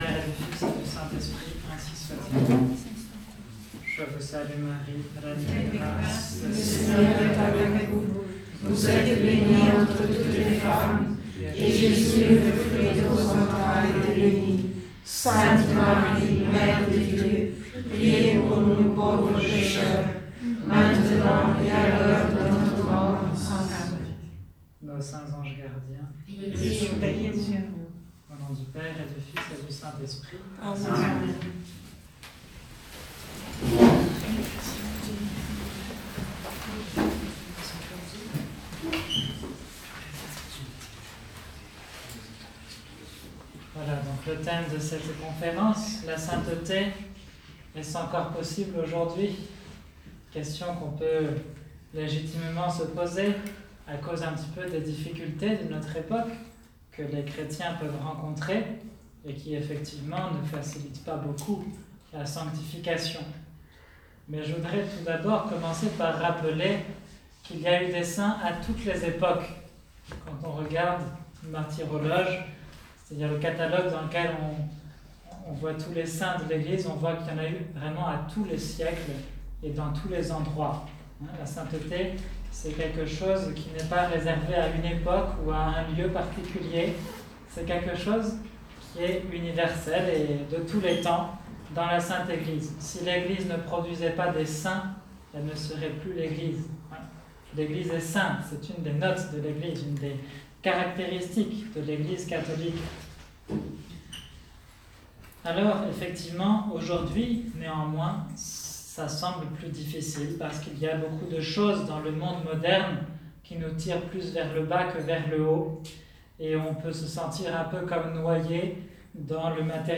Conférence sur la sainteté
Conférence-Sainteté-6-juillet-2019-av-prière.m4a